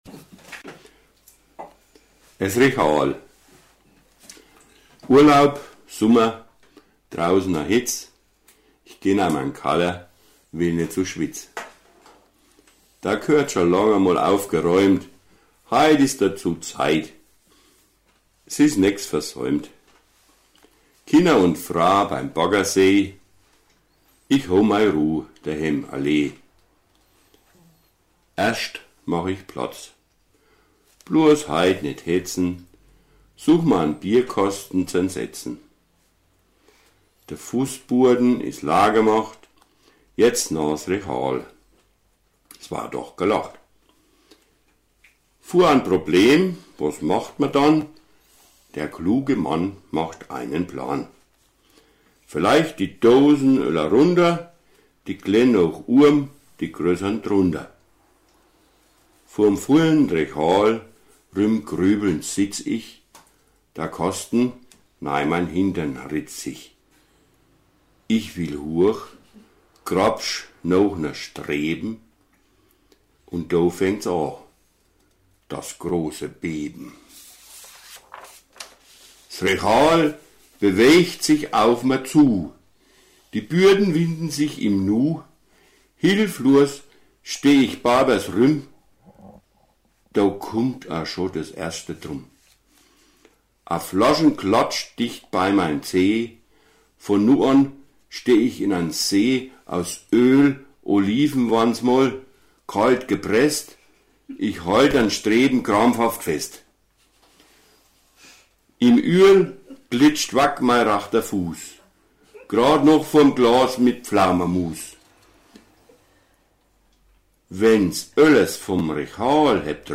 Dazu erhalten Sie eine CD mit Beiträgen in Grüber Mundart.